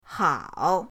hao3.mp3